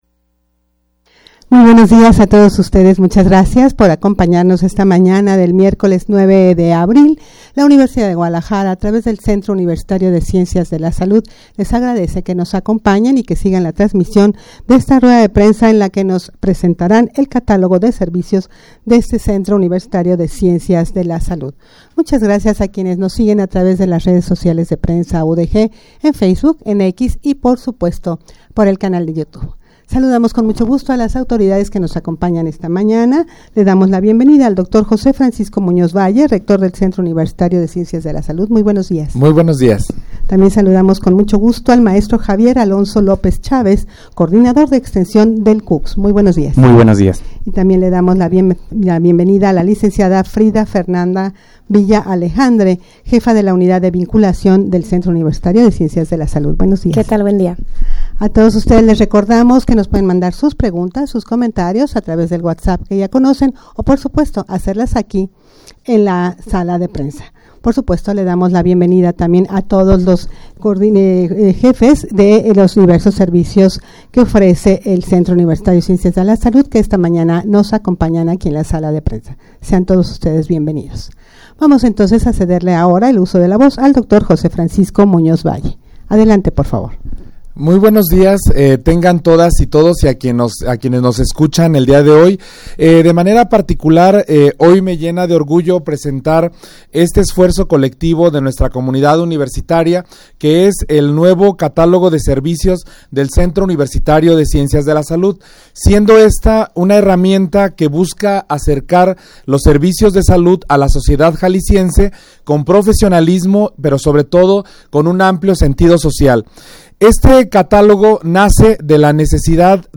rueda-de-prensa-presentacion-del-catalogo-de-servicios-del-cucs.mp3